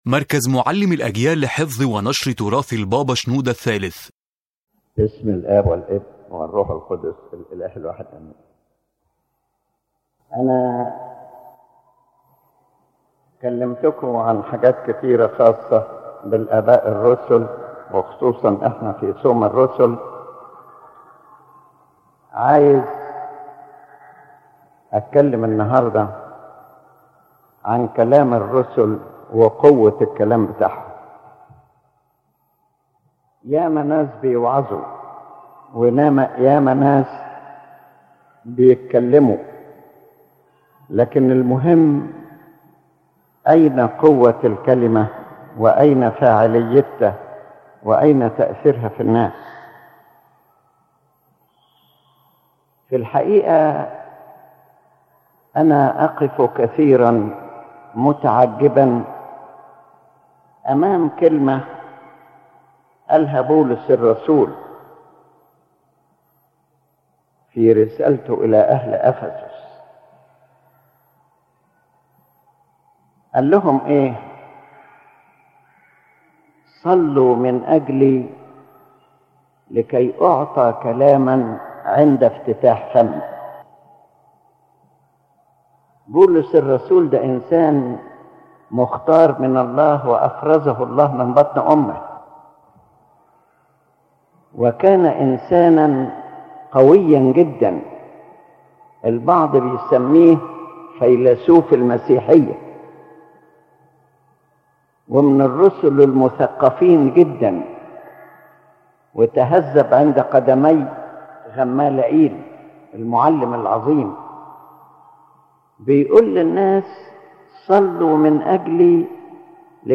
The General Message of the Lecture